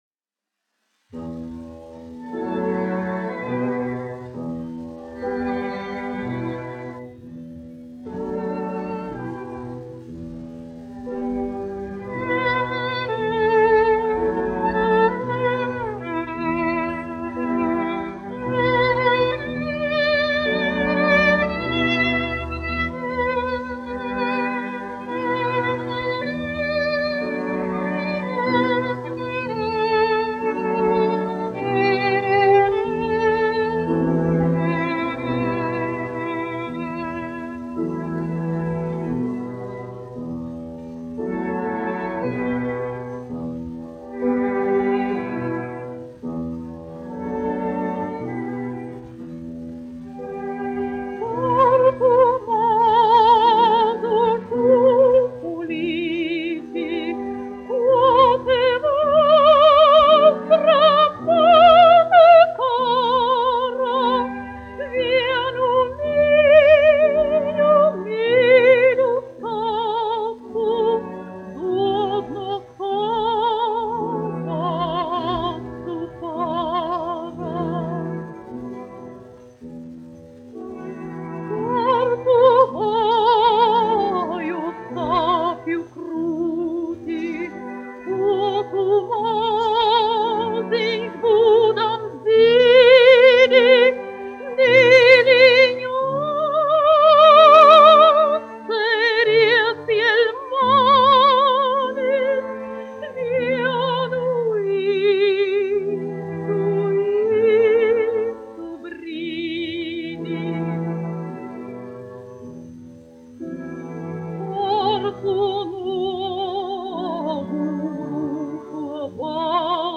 1 skpl. : analogs, 78 apgr/min, mono ; 25 cm
Dziesmas (vidēja balss) ar orķestri
Skaņuplate